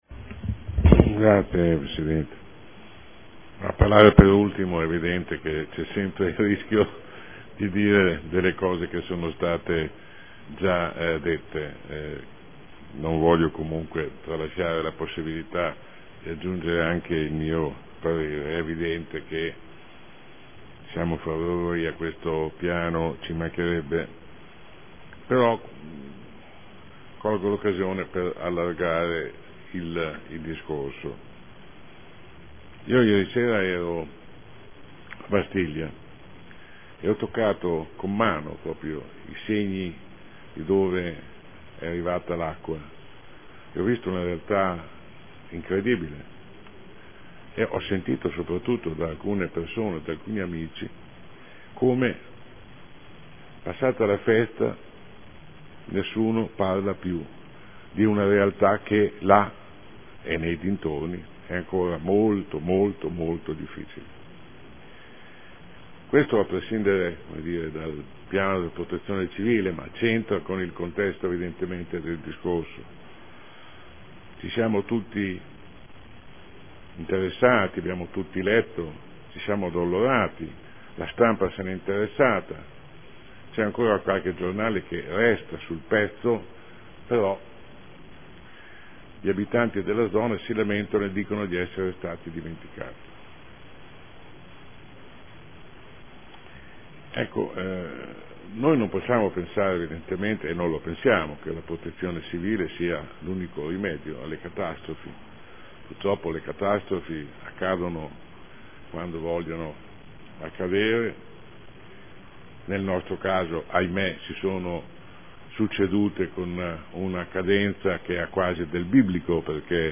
Sandro Bellei — Sito Audio Consiglio Comunale
Seduta del 20/03/2014 Aggiornamento del Piano comunale di protezione civile